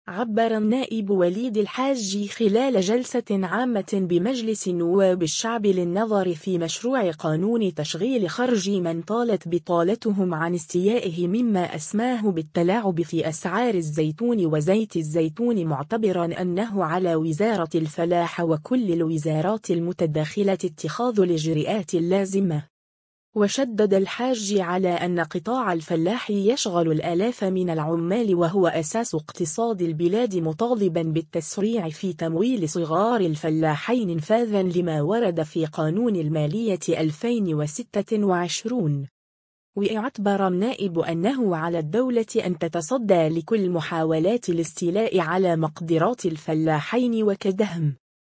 عبّر النائب وليد الحاجي خلال جلسة عامة بمجلس نواب الشعب للنظر في مشروع قانون تشغيل خرجي من طالت بطالتهم عن استياءه مما أسماه بالتلاعب في أسعار الزيتون وزيت الزيتون معتبرا أنه على وزارة الفلاحة وكل الوزارات المتداخلة اتخاذ الاجرءات اللازمة.